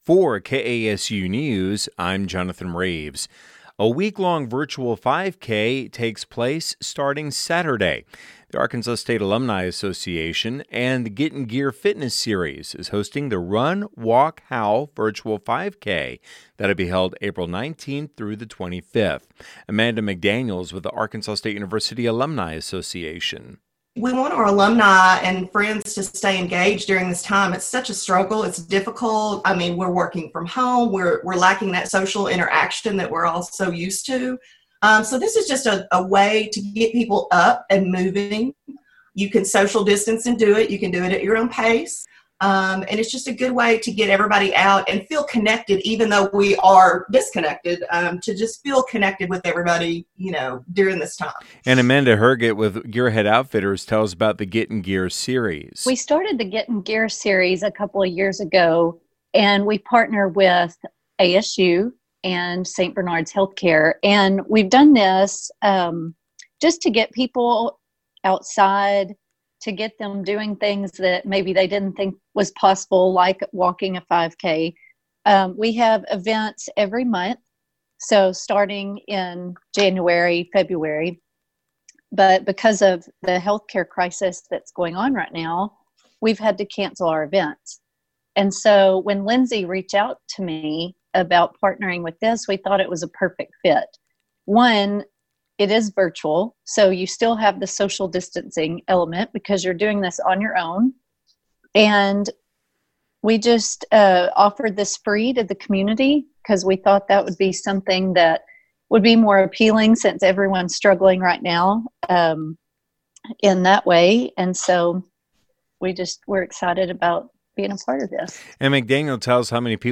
Interview About 'Run Walk Howl' Virtual 5K to Promote Fitness, April 19-25